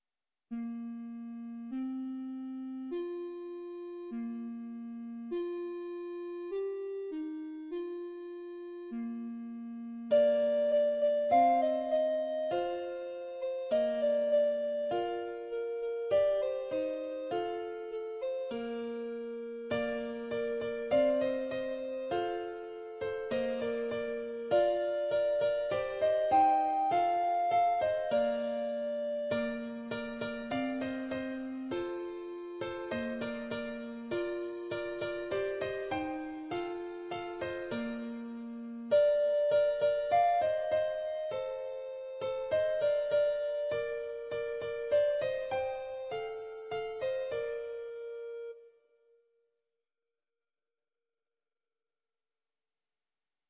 ‘Alles zwijgt thans’ is de vertaling van Mozarts canon ‘Alles schweiget’ – in een vertaling die mooier is dan het romantisch origineel.